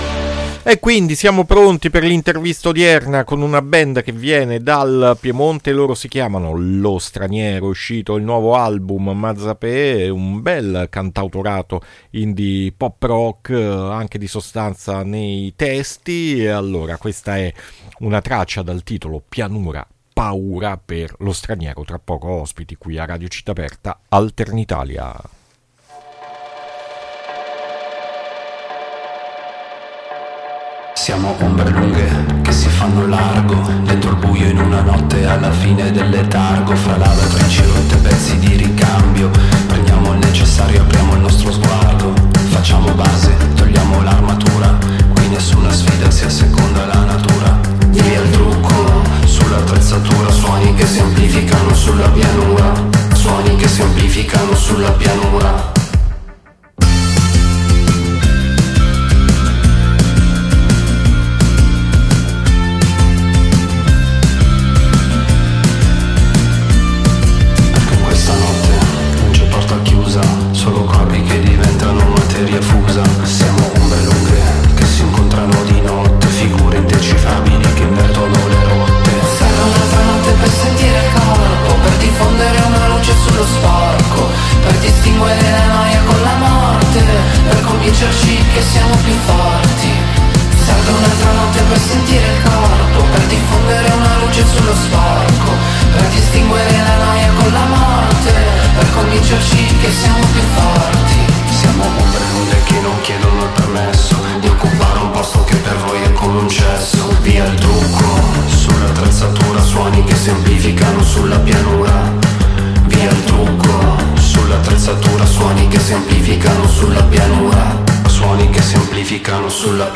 INTERVISTA LO STRANIERO AD ALTERNITALIA 7-3-3025